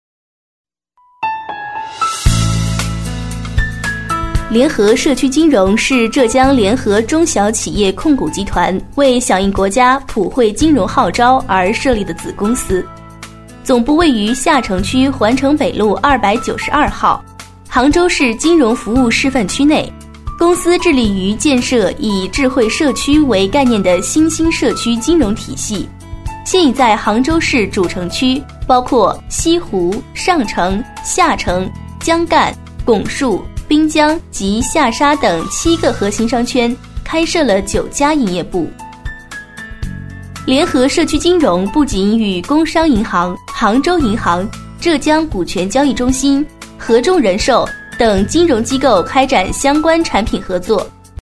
100%人工配，价格公道，配音业务欢迎联系：
【专题】联合社区女35
【专题】联合社区女35.mp3